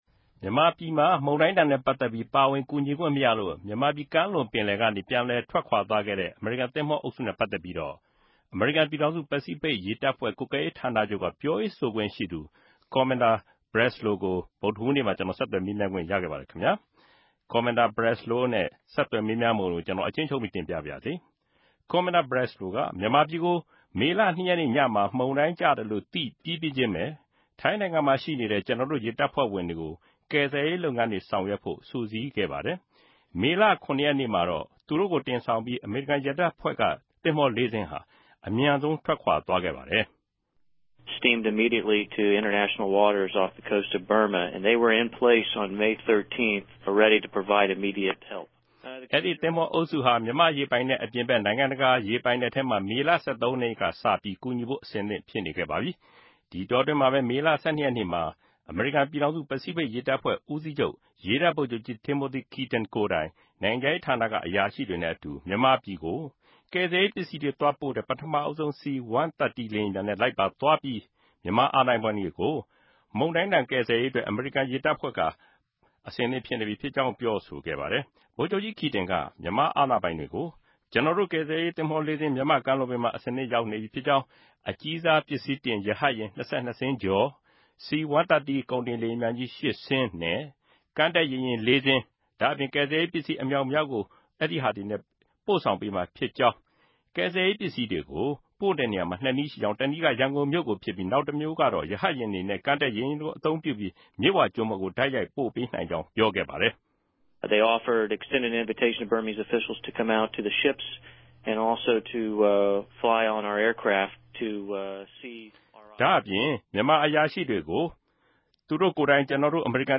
ဆက်သြယ်မေးူမန်းခဵက်၊ အပိုင်း (၁)